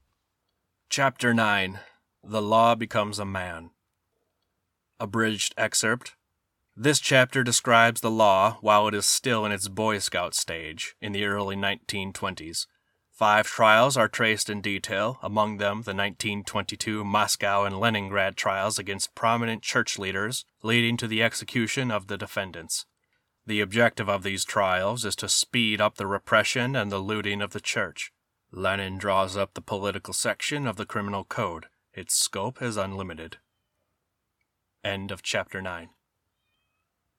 The Gulag Archipelago ABRIDGED (Chapter 09) Audiobook | Poal: Say what you want.
I give my apologies to the no doubt often mispronounced Russian names and places. Additional apologies for sometimes mispronounced English words…